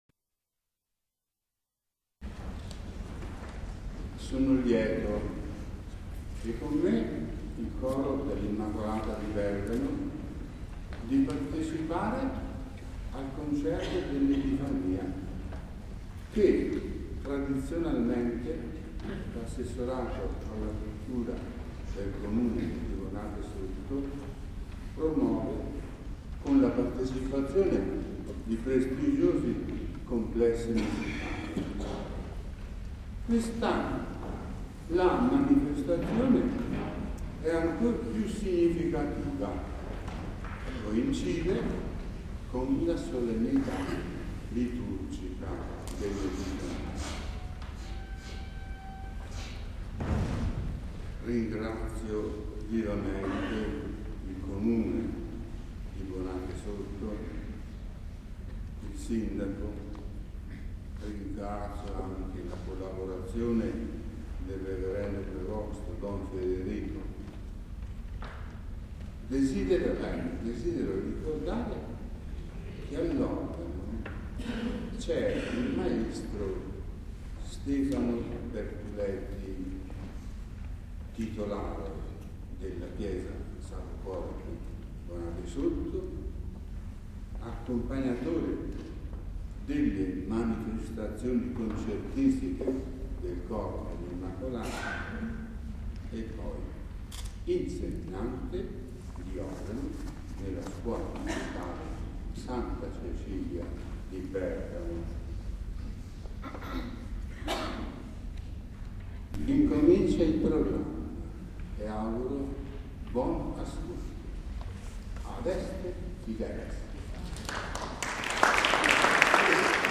Concerto dell'Epifania
Chiesa di San Giorgio